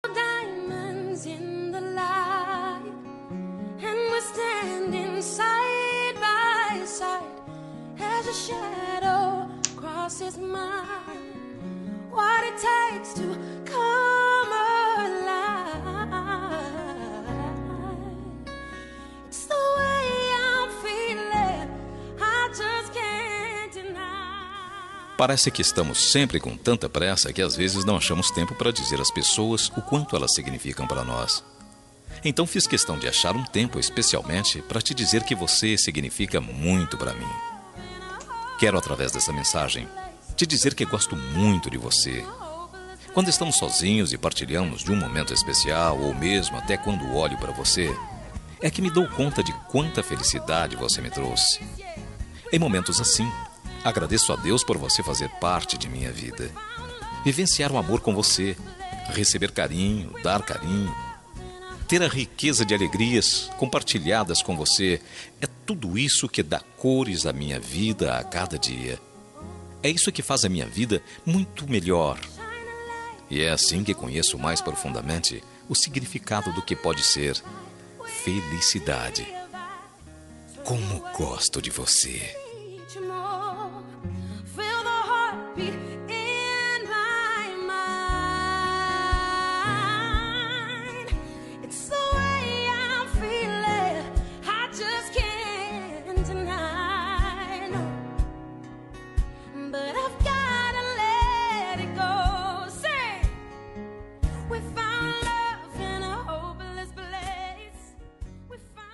Romântica para Esposa – Voz Masculina – Cód: 6726